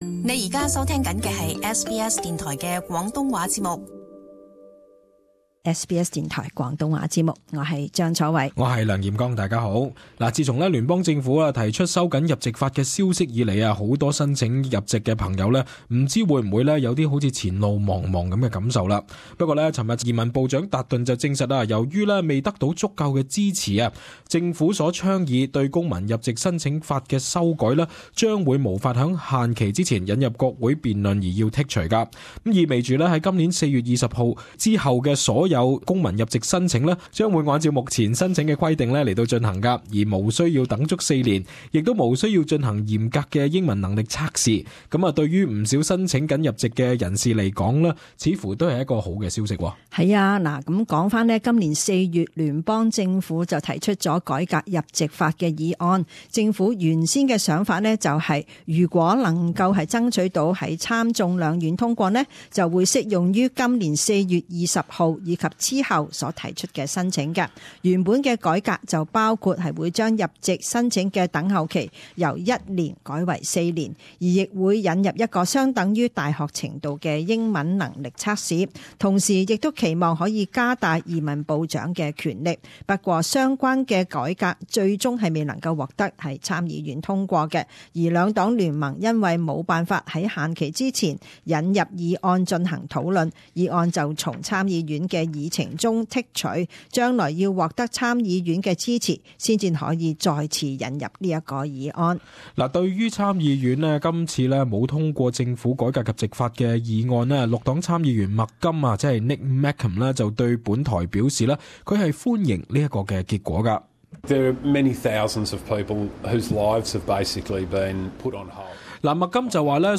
【時事報導】公民入籍法改革草案遭剔除